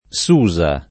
Susa [ S2@ a ]